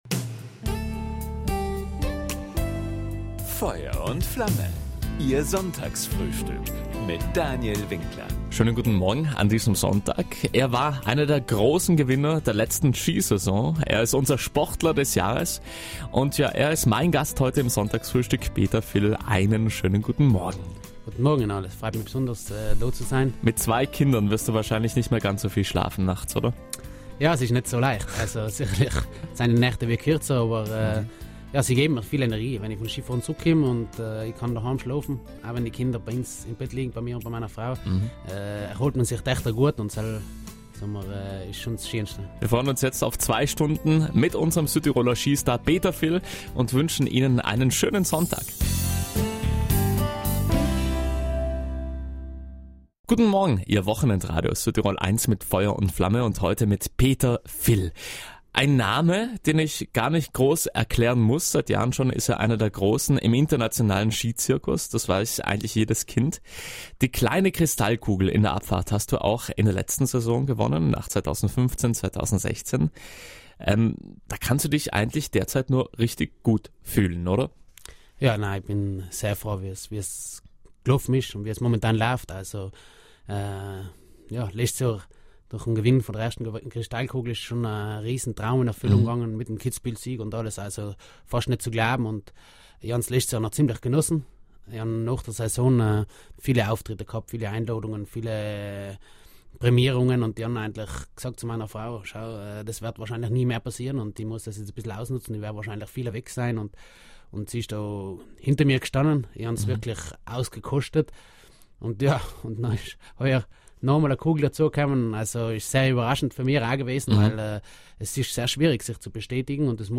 Die abgelaufene Ski-Saison war für den Kastelruther eine absolute Traumsaison. Nicht nur davon erzählte er uns am Sonntag in einem ausführlichen Wochenend-Ratscher.